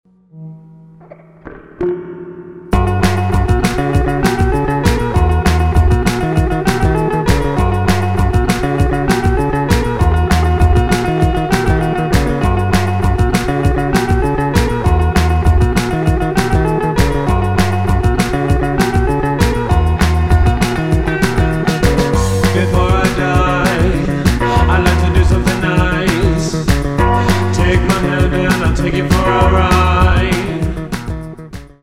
• Качество: 320, Stereo
душевные
alternative
indie rock
new wave